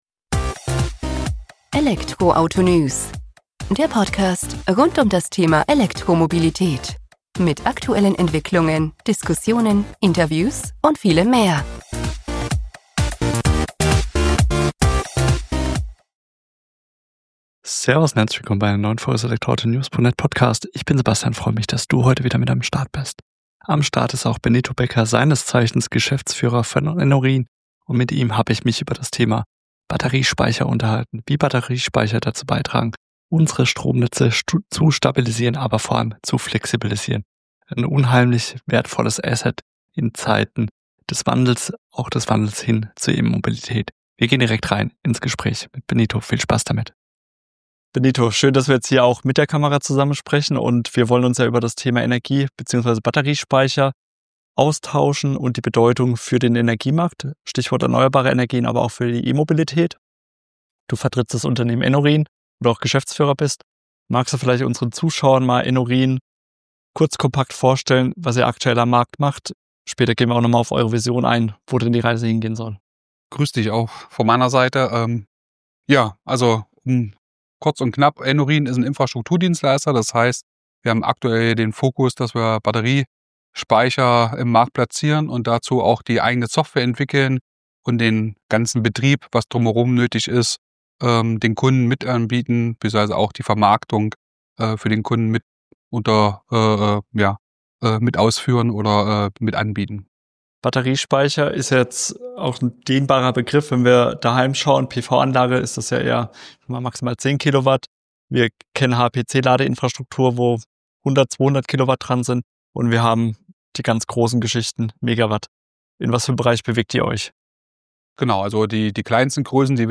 Im Gespräch wird schnell klar, warum Wind- und Solarenergie allein nicht ausreichen.